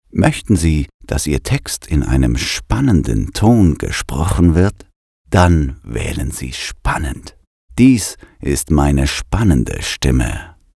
Male
Adult (30-50)
soft, serious, musically, with a large sonor presence, deep, warm, friendly, authoritative, enthusiastic, and – most importantly – believable and trustworthy.
Narration
Thrilling And Mysterious
1114DEMO_spannend.mp3